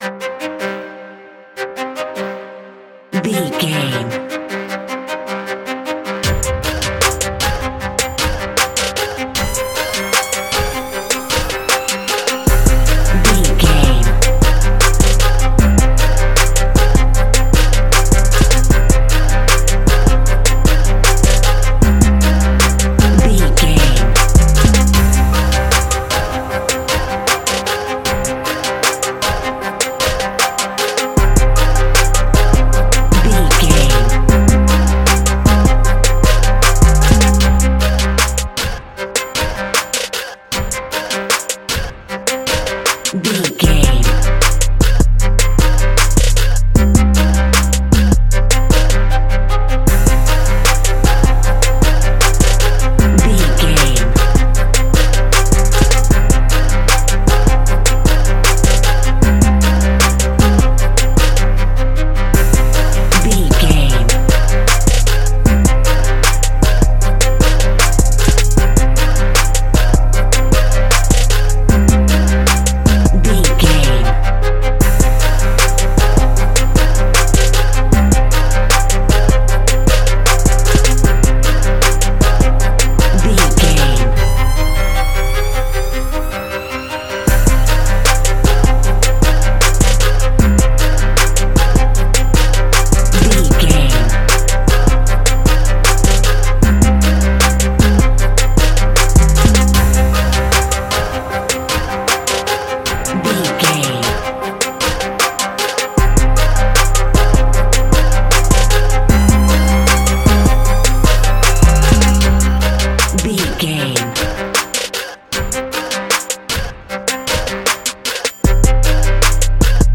A Dark Rap Beat.
Aeolian/Minor
SEAMLESS LOOPING?
DOES THIS CLIP CONTAINS LYRICS OR HUMAN VOICE?
WHAT’S THE TEMPO OF THE CLIP?
synths
synth lead
synth bass
synth drums